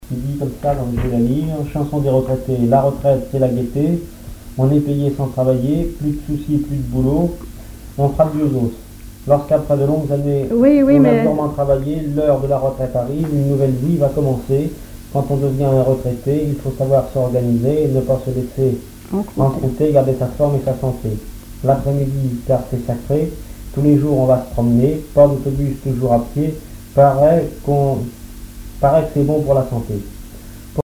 collecte en Vendée
Pièce musicale inédite